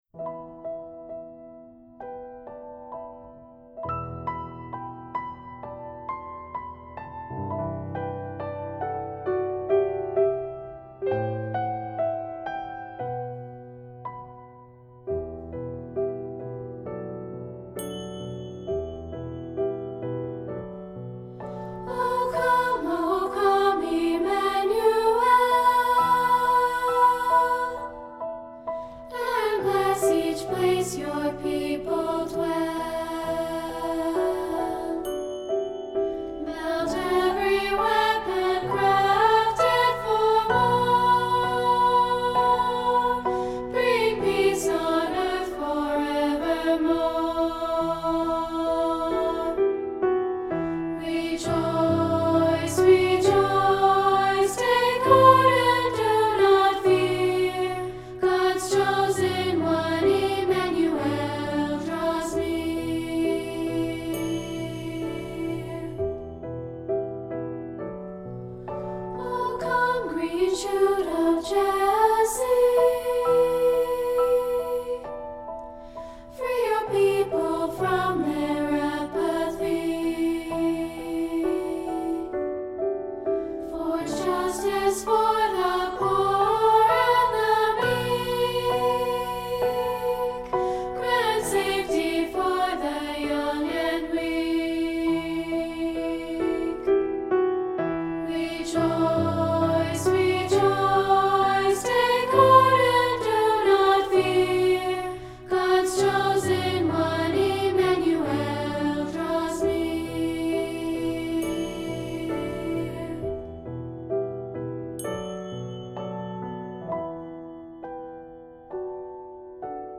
Voicing: Unison/two